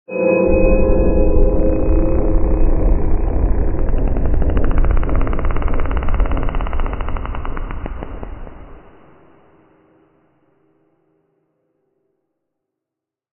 Minecraft Version Minecraft Version 1.21.5 Latest Release | Latest Snapshot 1.21.5 / assets / minecraft / sounds / ambient / cave / cave19.ogg Compare With Compare With Latest Release | Latest Snapshot
cave19.ogg